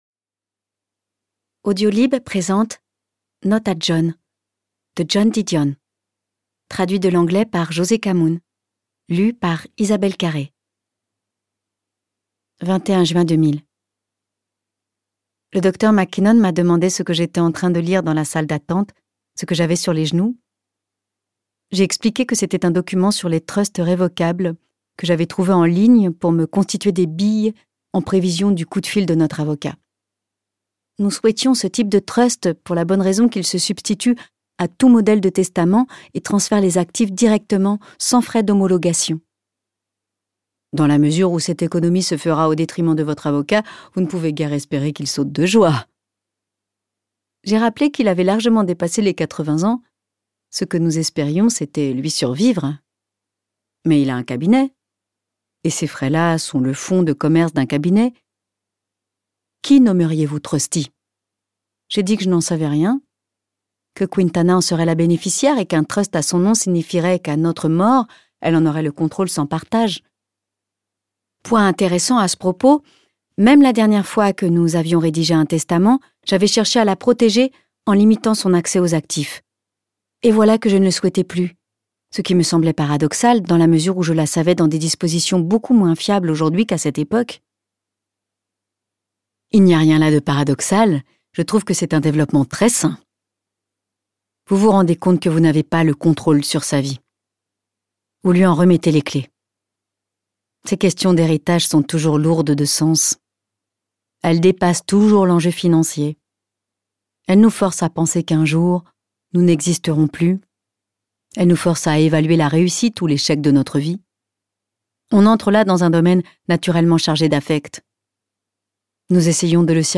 Extrait gratuit - Notes à John de Isabelle Carré, Joan DIDION, Josée Kamoun